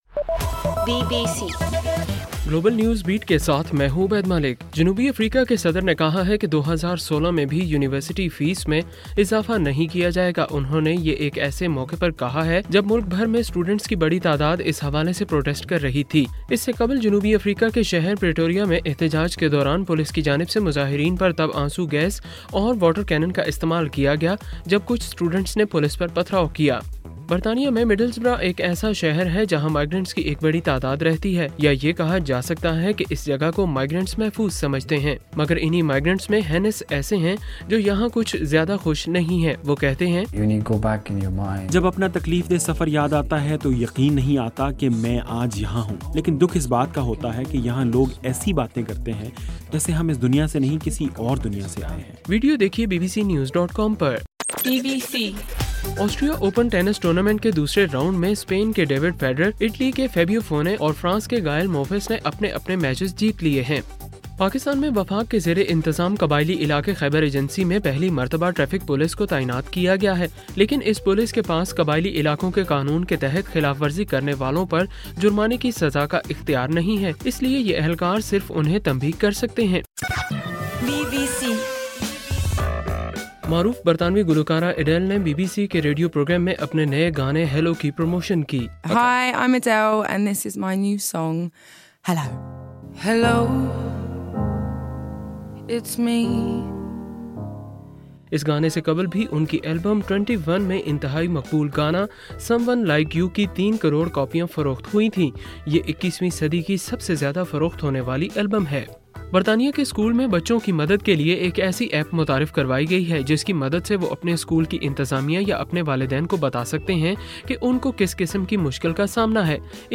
اکتوبر 23: رات 9 بجے کا گلوبل نیوز بیٹ بُلیٹن